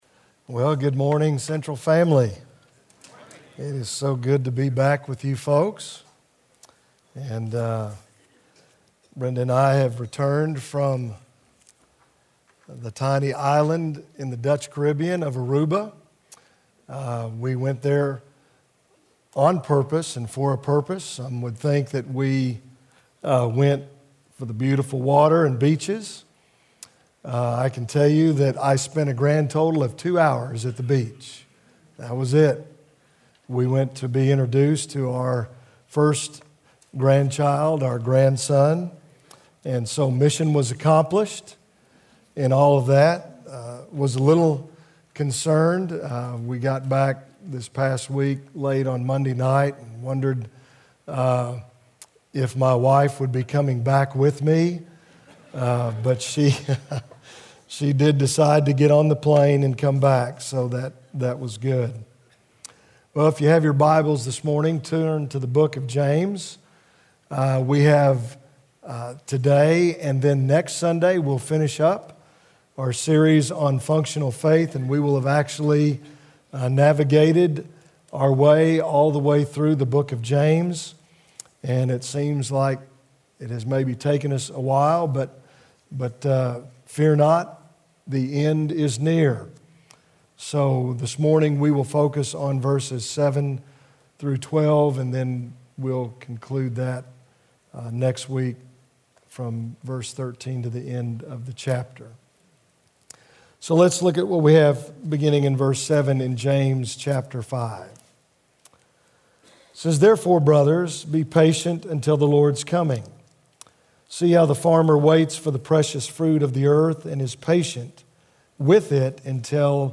A message from the series "Functional Faith."